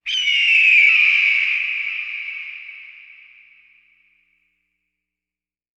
Falcon.ogg